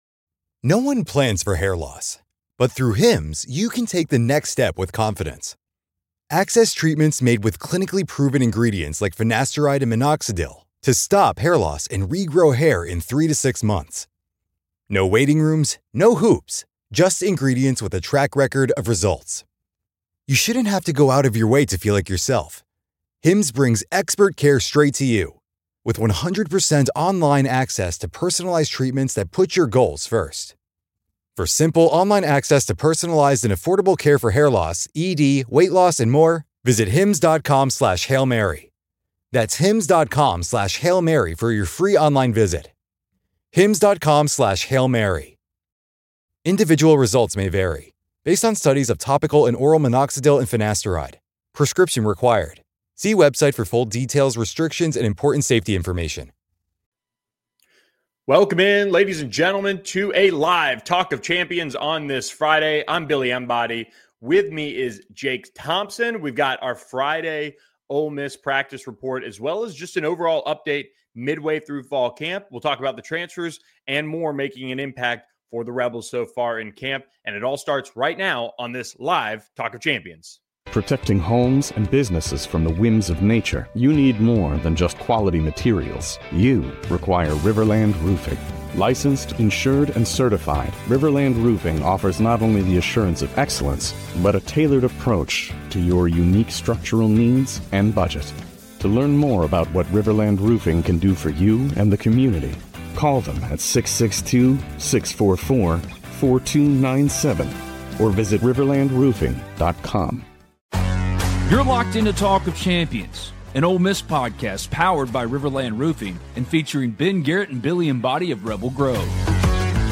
Practice Report: Ole Miss football is midway through fall camp